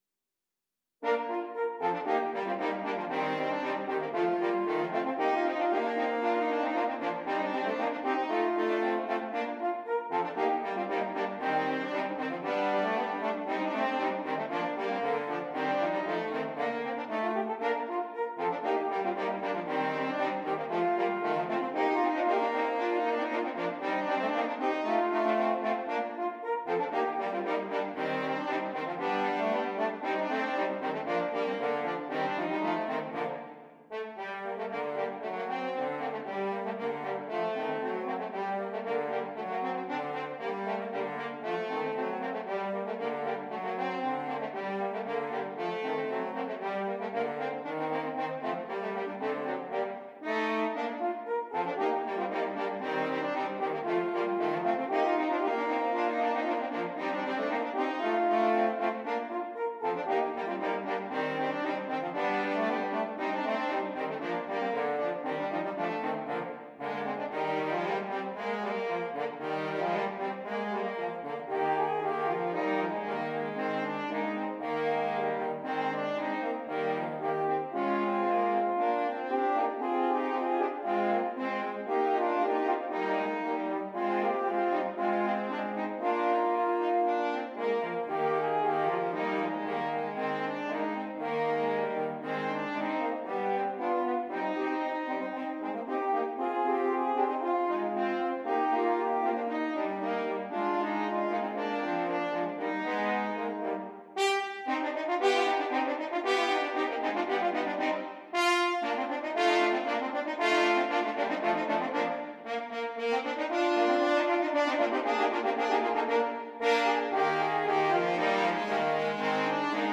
3 Horns